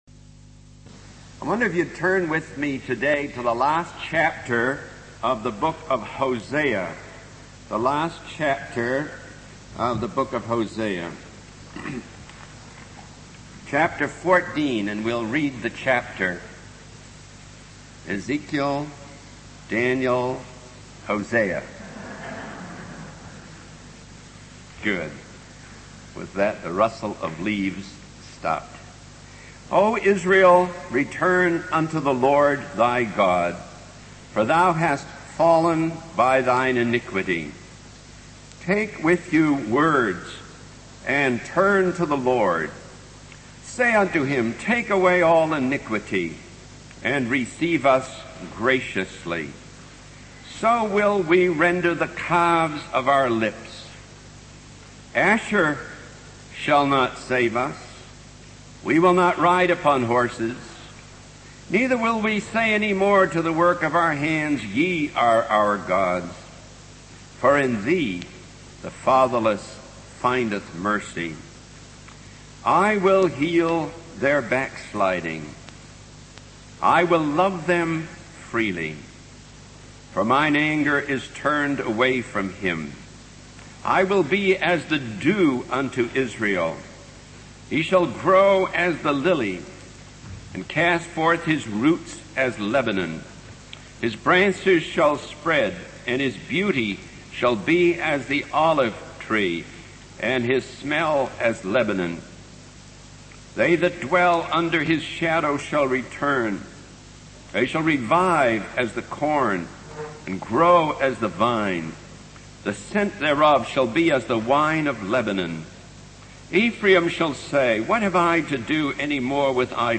In this sermon, the preacher emphasizes the consequences of sin and backsliding in a person's life. He uses examples like a violin under pressure and a pilot following a flight plan to illustrate the idea that true freedom comes from following God's plan. The preacher also highlights the importance of confession and repentance, stating that God is the God of second chances.